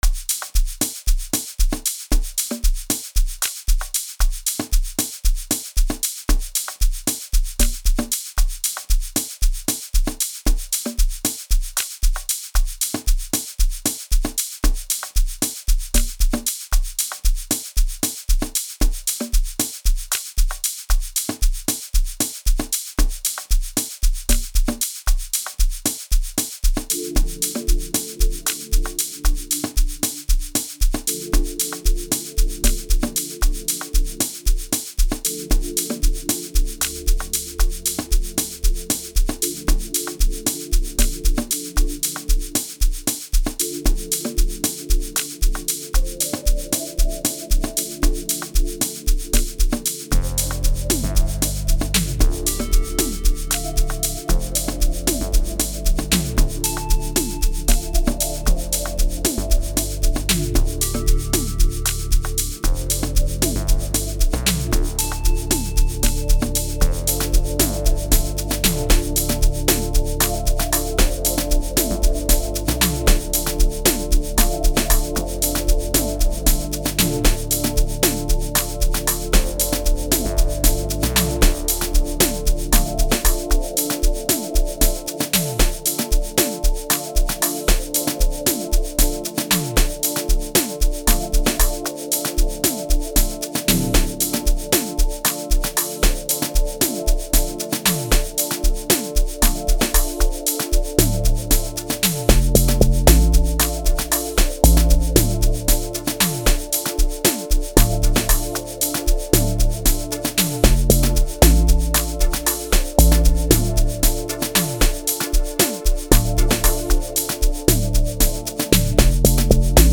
05:51 Genre : Amapiano Size